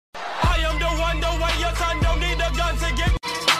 I am the one - Meme Sound Effect